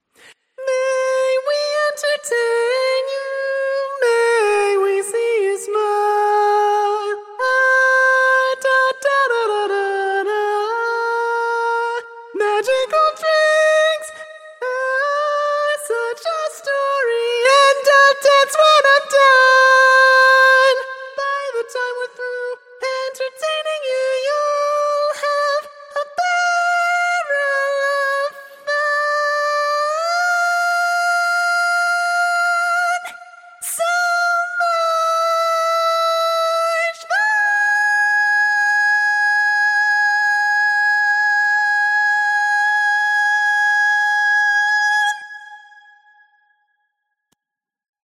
Key written in: A♭ Major
Type: Female Barbershop (incl. SAI, HI, etc)
Each recording below is single part only.